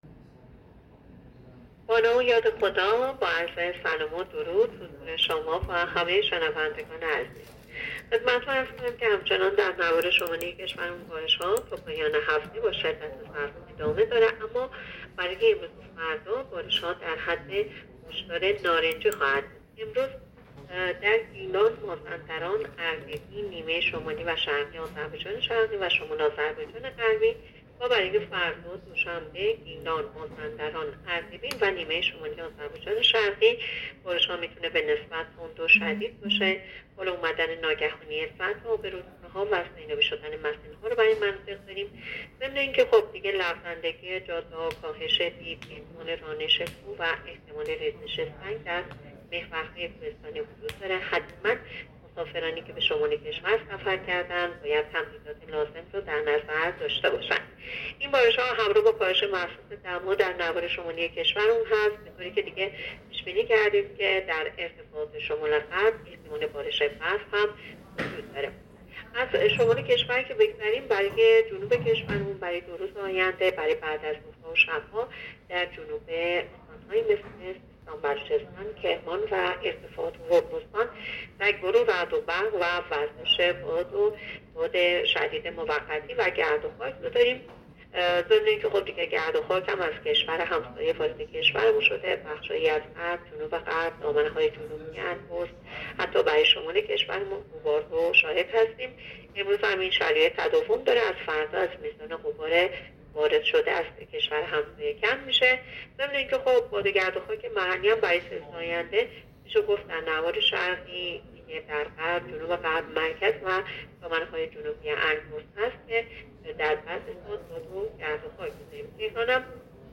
گزارش رادیو اینترنتی پایگاه‌ خبری از آخرین وضعیت آب‌وهوای ۳۰ شهریور؛